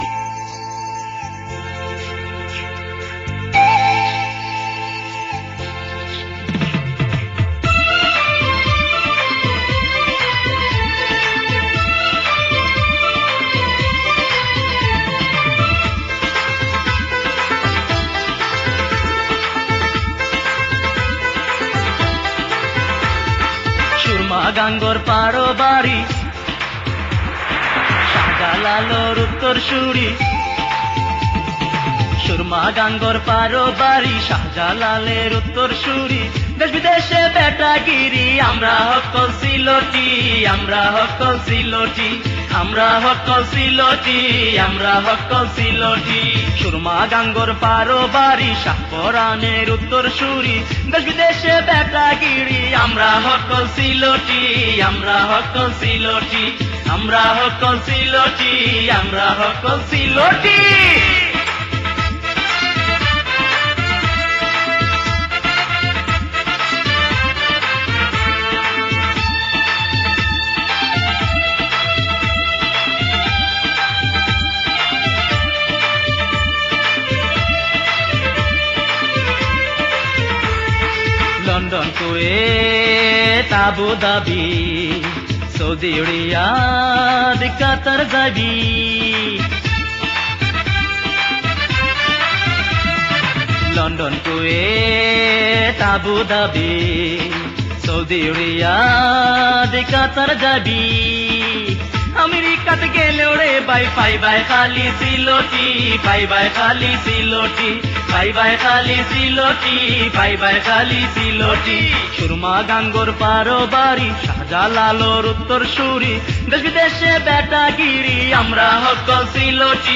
Thames er Kuley Surmar Dheu broadcasted on Sylheti Music Radio